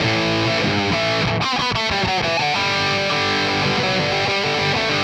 AM_RawkGuitar_95-A.wav